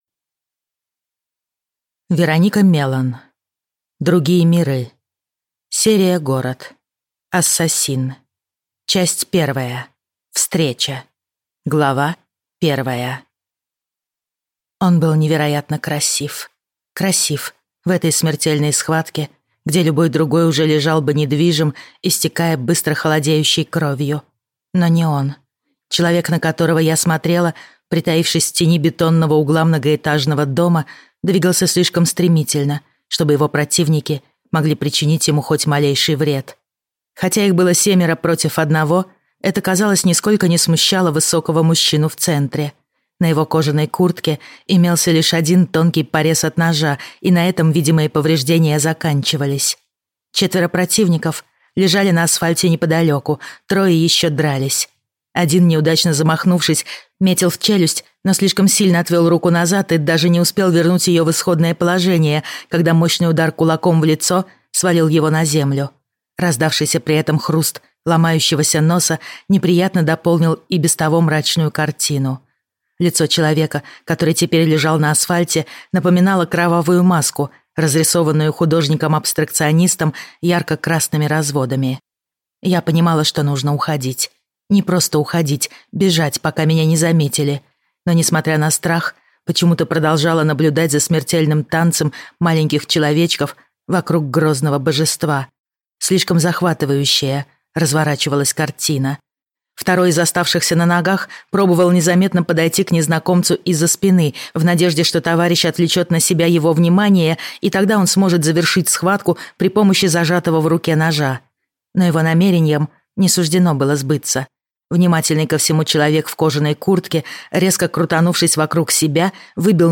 Аудиокнига Ассасин - купить, скачать и слушать онлайн | КнигоПоиск